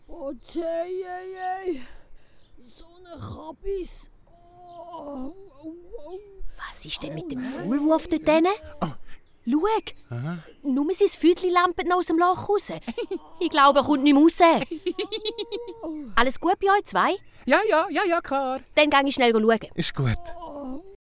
★ Abentüür uf de Wiese Folge 2 ★ Dialekt Hörspiel ★ Löffelspitzer